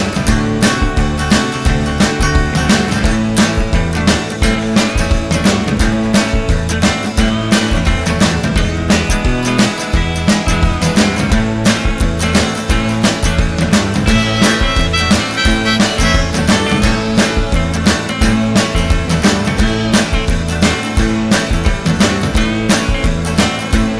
No Harmonica Pop (1960s) 2:21 Buy £1.50